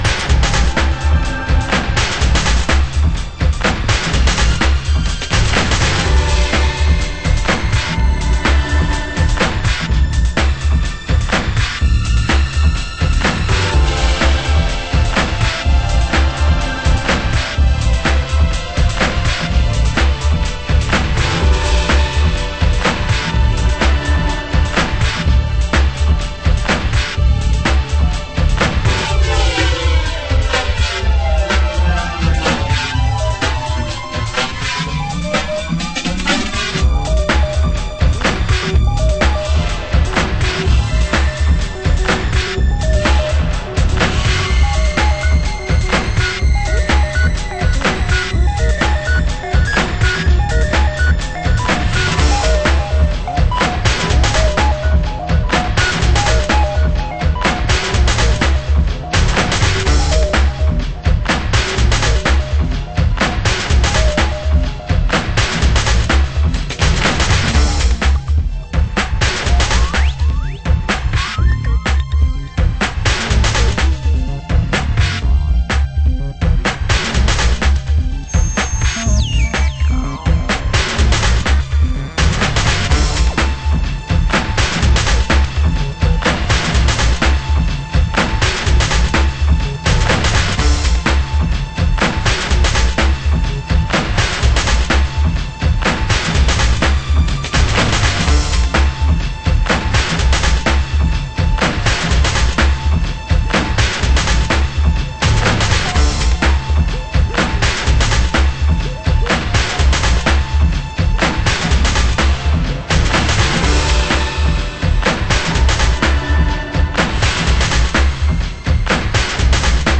HOUSE MUSIC
盤質：B1のイントロに約5ミリ程の傷ノイズ 有/少しチリノイズ有　　　ジャケ：良好